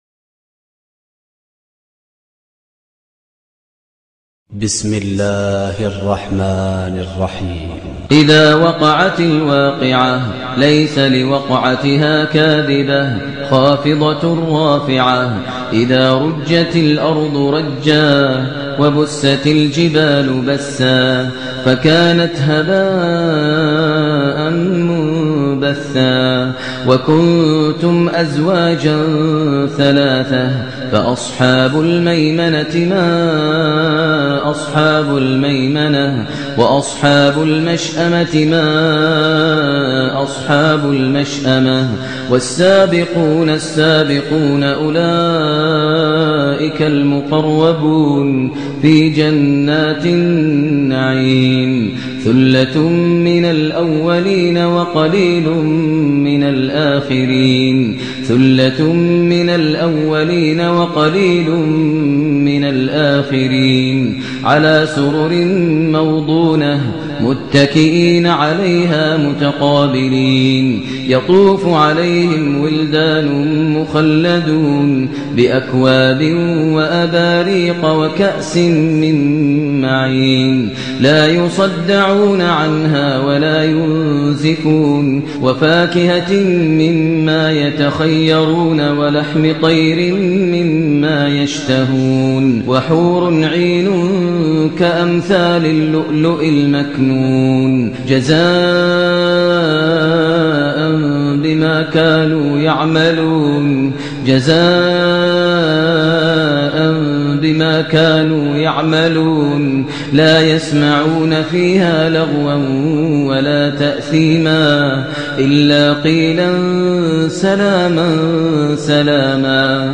سورة الواقعة بصوت مؤثرجدآ يريح القلوب ..." ختمة سورة الواقعة لزيادة الرزق كاملة مكتوبة"